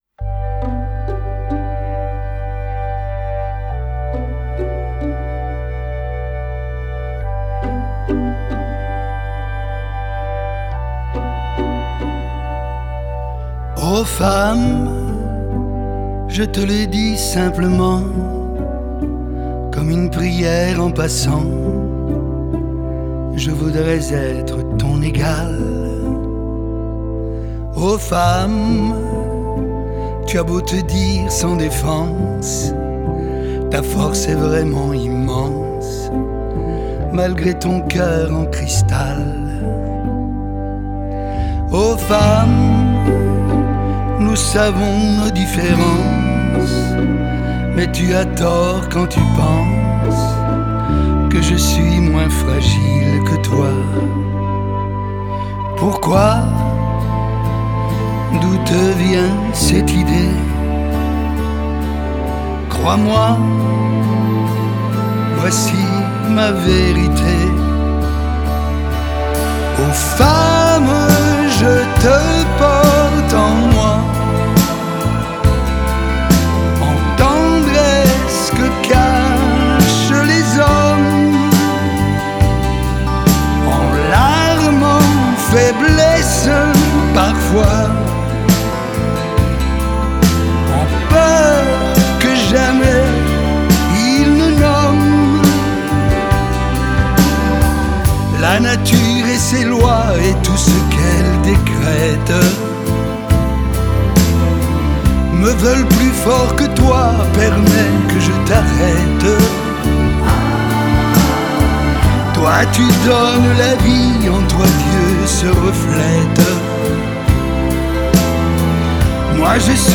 Genre: French Pop, French Chanson